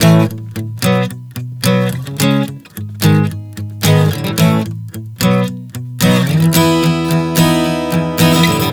Prog 110 A-B-C#m-E.wav